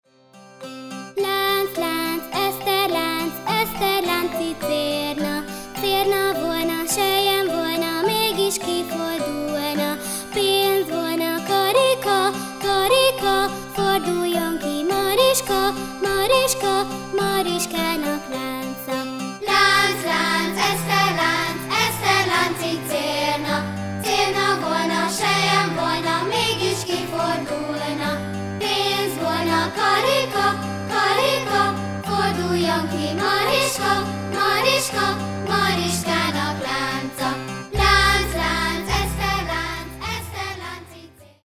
dalok óvodásoknak
A dalokat kisiskolások éneklik.